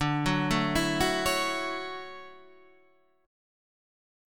DM9 Chord
Listen to DM9 strummed